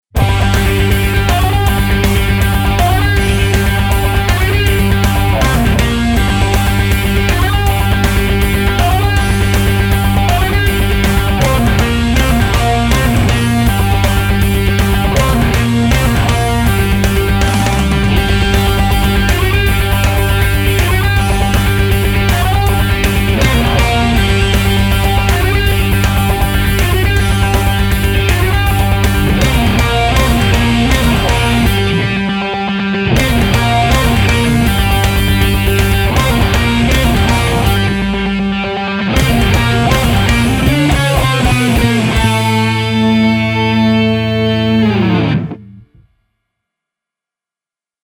Инструментальный отрезок композиции